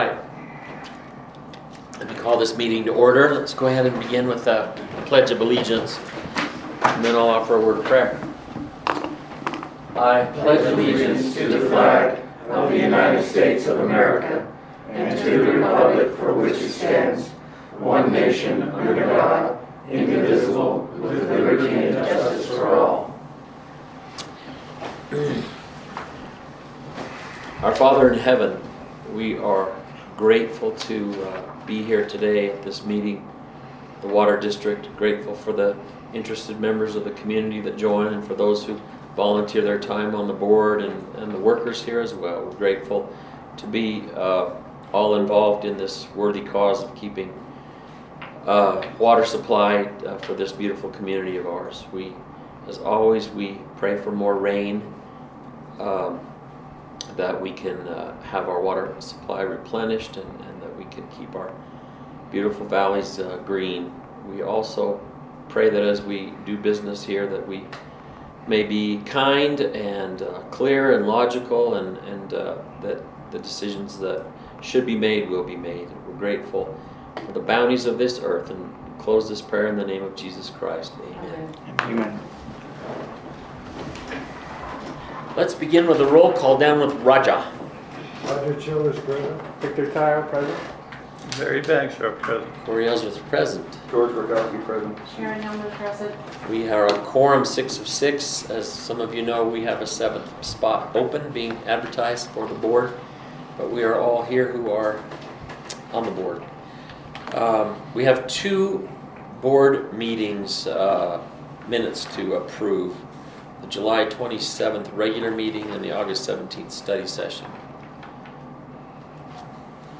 Regular Meeting